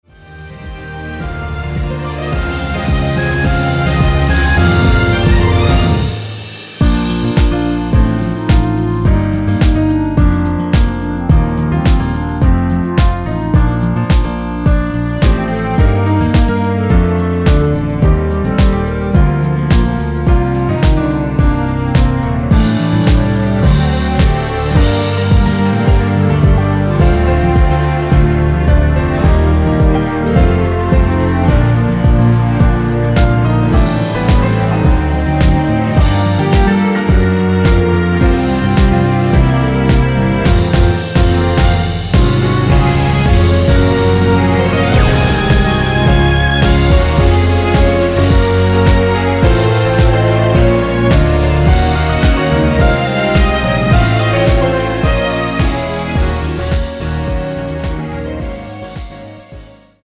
원키에서(-3)내린 멜로디 포함된 MR입니다.
Bb
앞부분30초, 뒷부분30초씩 편집해서 올려 드리고 있습니다.
중간에 음이 끈어지고 다시 나오는 이유는
(멜로디 MR)은 가이드 멜로디가 포함된 MR 입니다.